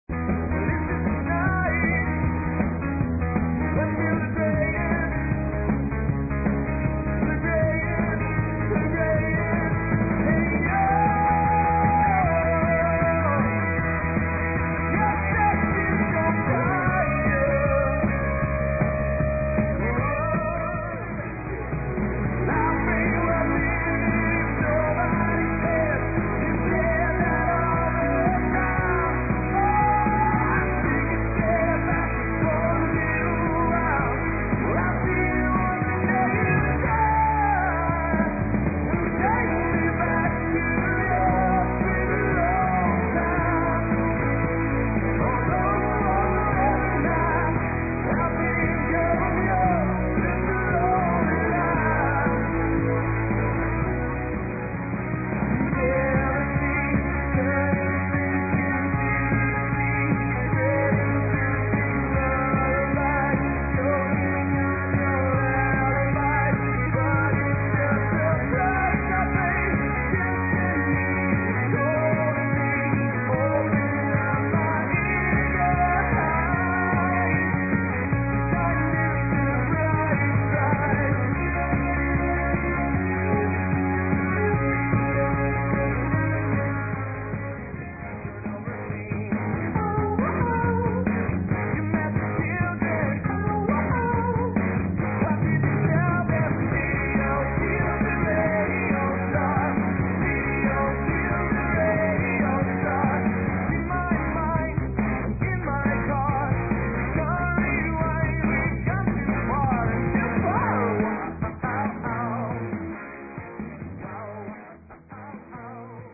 small acoustic trios to the full rock band party machine
top 40 rock, pop, classics and dance